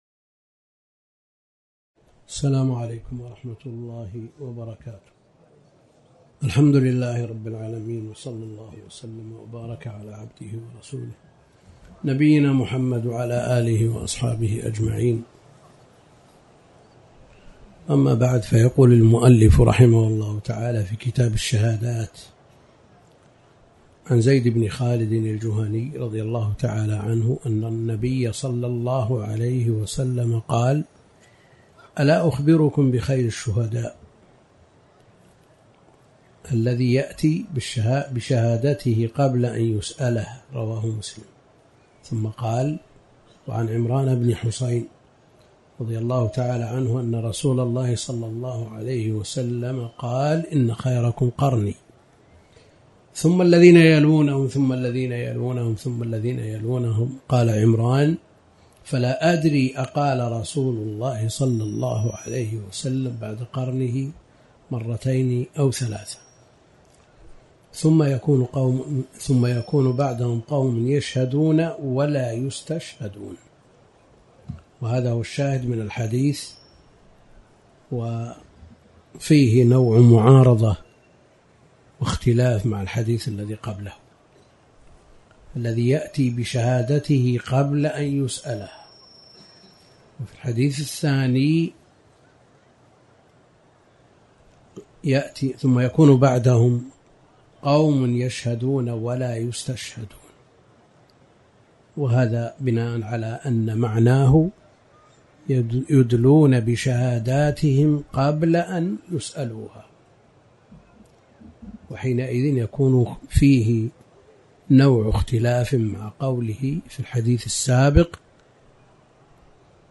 تاريخ النشر ٥ ذو القعدة ١٤٣٨ هـ المكان: المسجد الحرام الشيخ